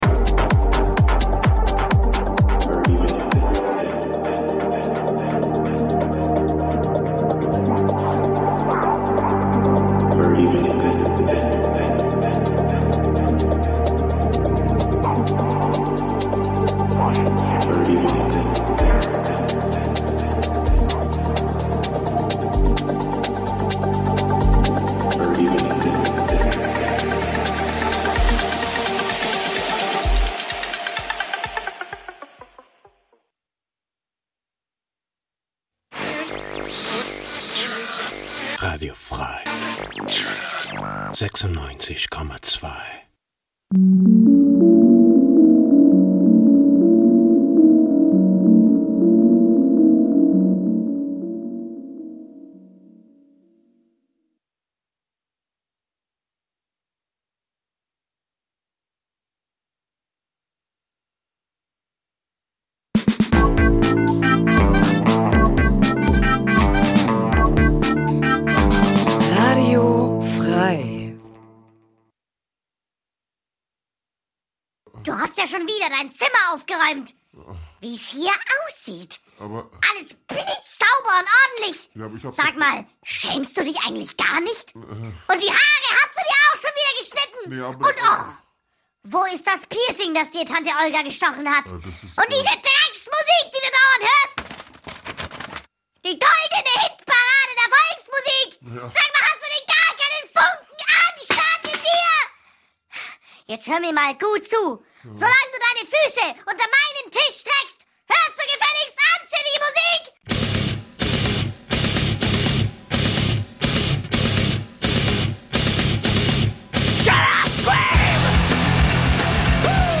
...24 Jahre of total Krach... die beste Mischung aus genialen Neuvorstellungen und unerl�sslichen Konzerttips... aus dem old school, Metal-, Rock 'n' Roll-, Grind- und Hardcore- und sonstigen "gute Musik"- Bereichen...